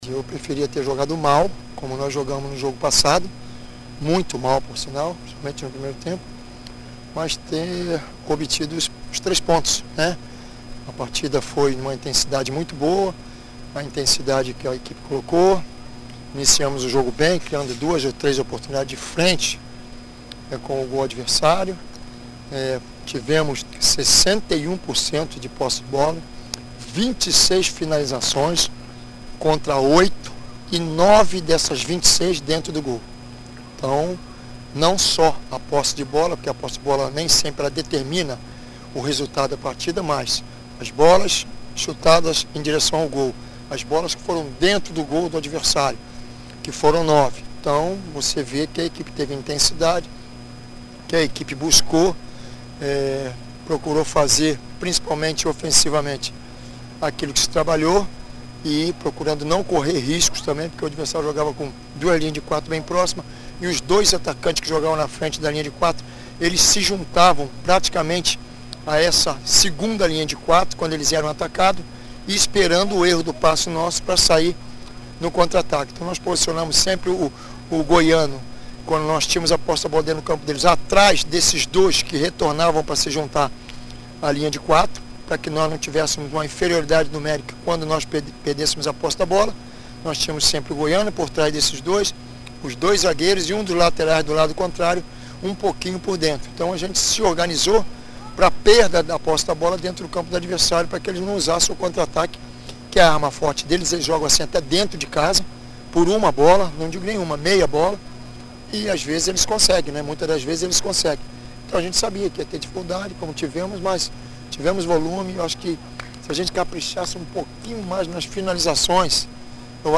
Diz que tem observado que a equipe tem cansado na etapa final e afirma ainda que o Sampaio precisa se impor fora de casa nos duelos de “seis pontos”. Confira o bate-papo em áudio abaixo: